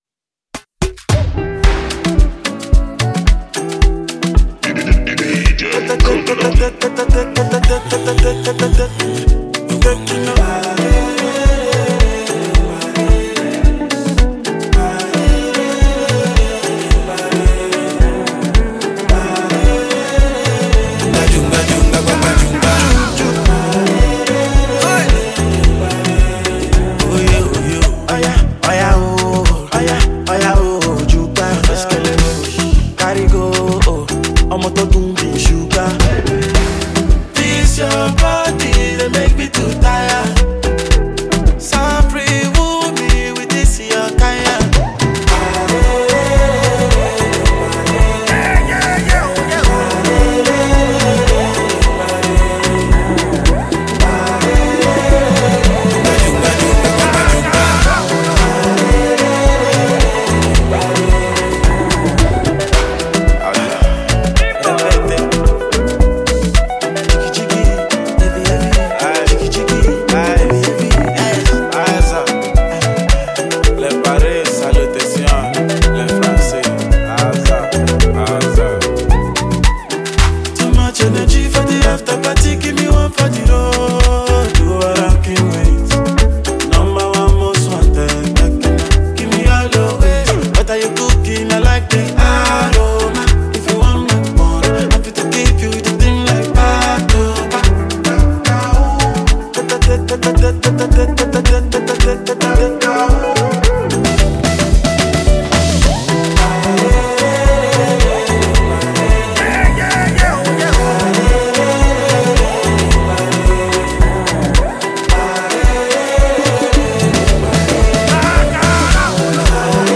Popular talented Nigerian singer and songwriter